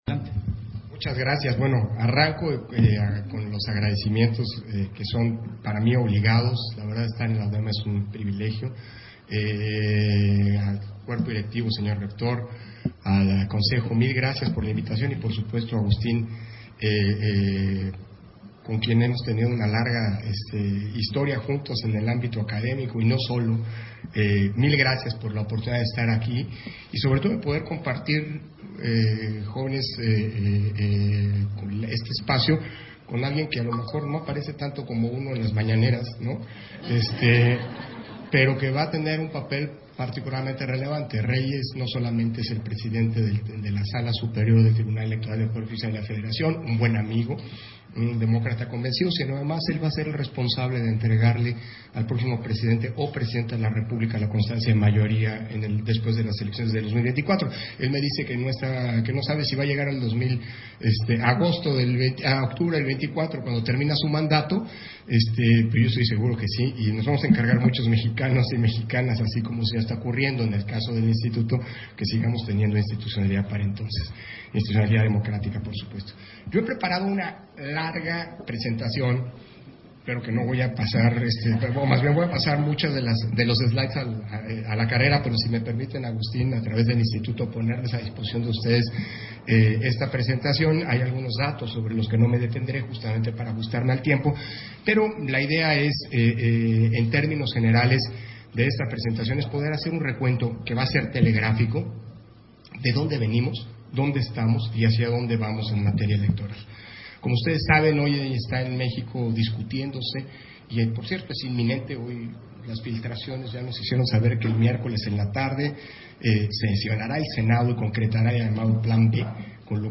300123_AUDIO_CONSEJERO-PDTE.-CÓRDOVA-CONFERENCIA-RETOS-Y-DESAFÍOS-DE-LAS-AUTORIDADES-ELECTORALES - Central Electoral